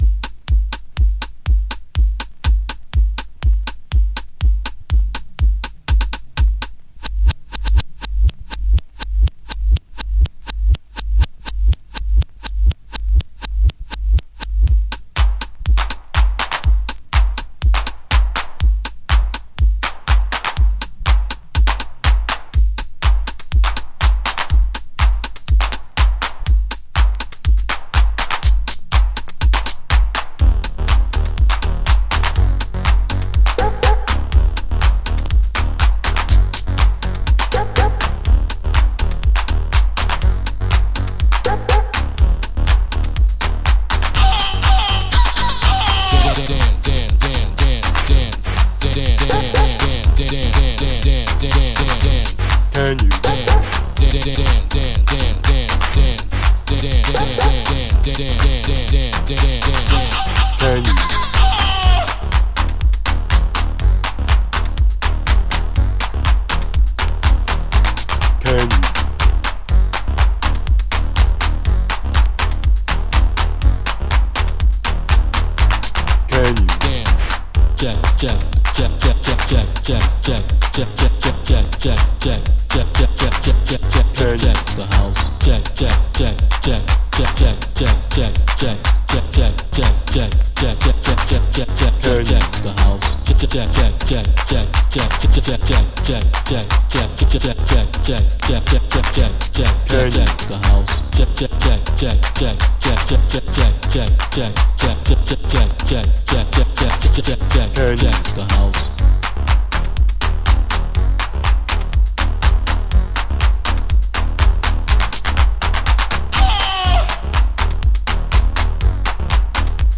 犬と人が吠える